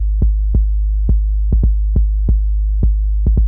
Index of /90_sSampleCDs/Best Service ProSamples vol.54 - Techno 138 BPM [AKAI] 1CD/Partition C/UK PROGRESSI
SUB BASS  -L.wav